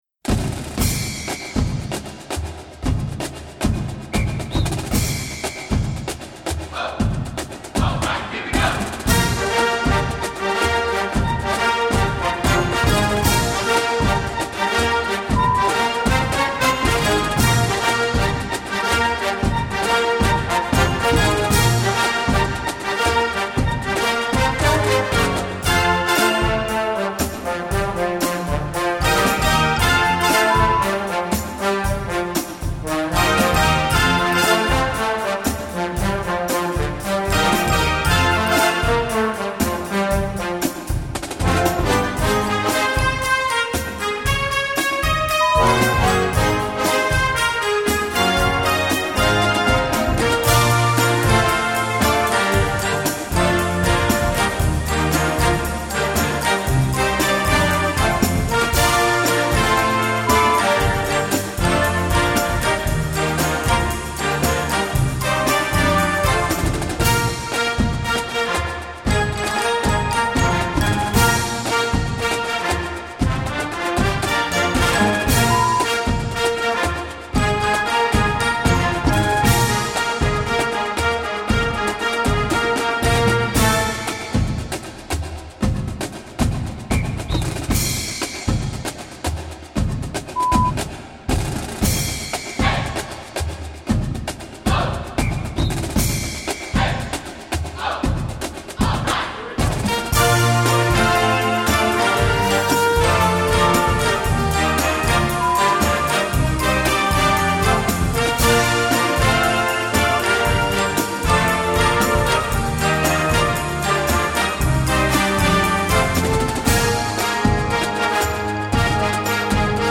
Filmmusik für Blasorchester
Marching-Band
Besetzung: Blasorchester